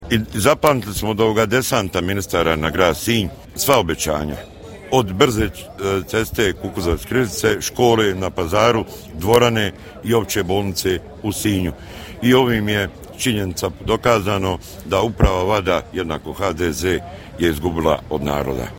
Poslušajte što je za rezultate kazao Miro Bulj: